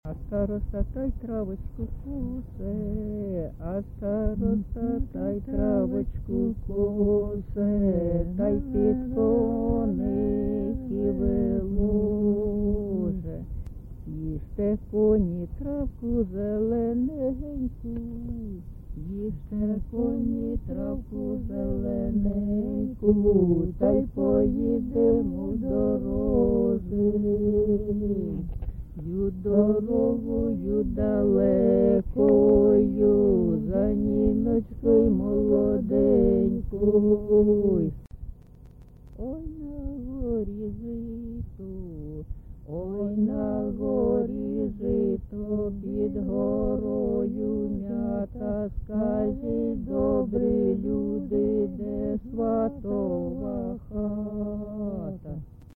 GenreWedding
Recording locationMarynivka, Shakhtarskyi (Horlivskyi) district, Donetsk obl., Ukraine, Sloboda Ukraine